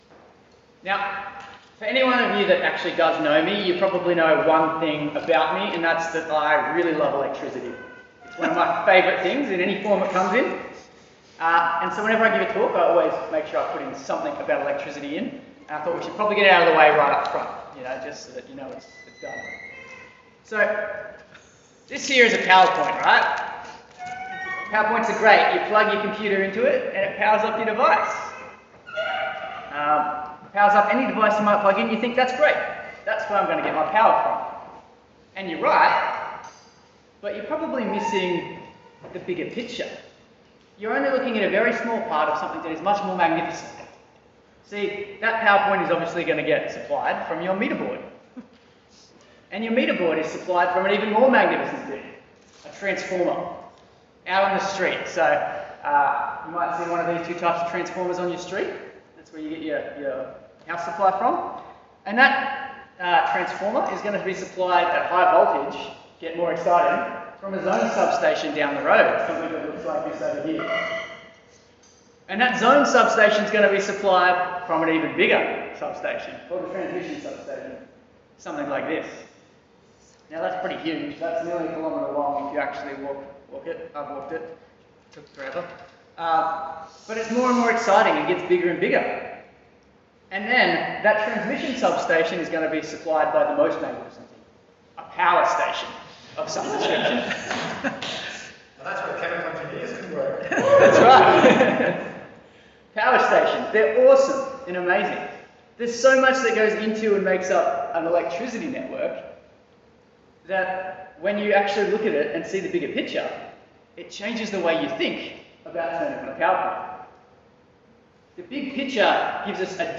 Passage: 2 Thessalonians 1:3-12 Talk Type: Bible Talk